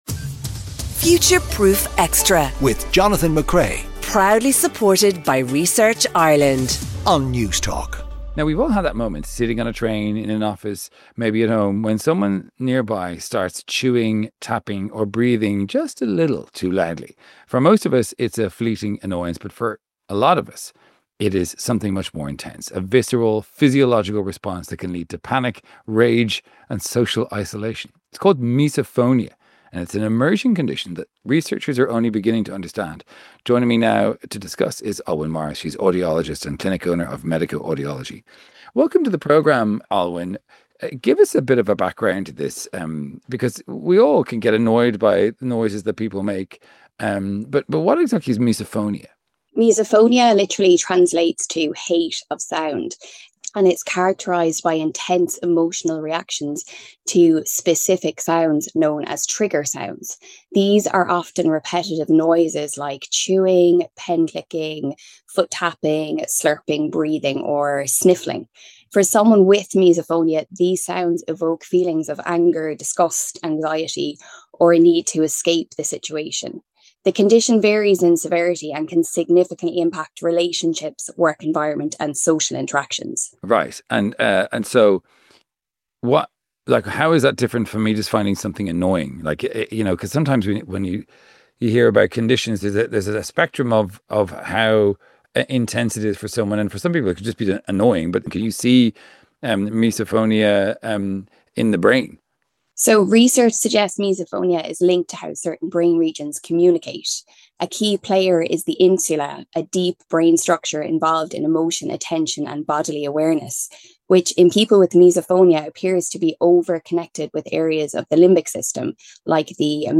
Jonathan responds to your texts and tweets, is joined in studio for all the latest science stories for Newsround and speaks to one of our two guests featured on the show.